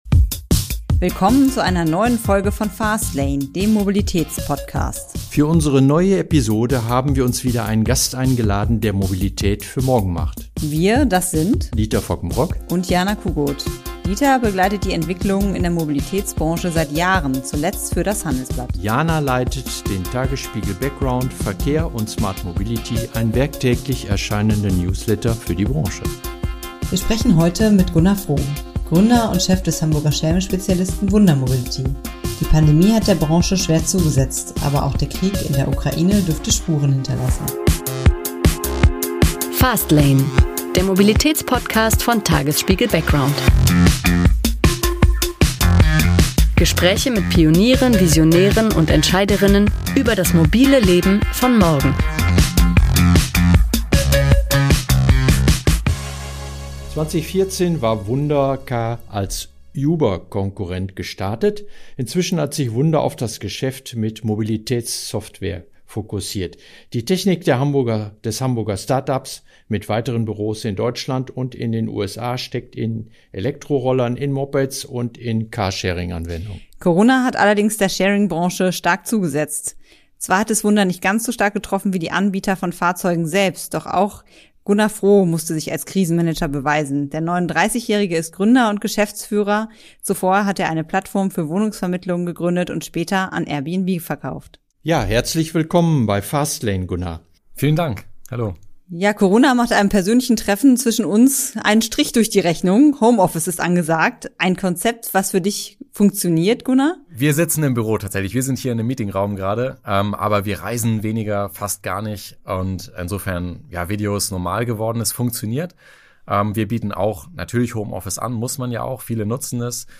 Ein Gespräch über E-Roller-Sharing auf dem Land, Mobilitätsplattformen und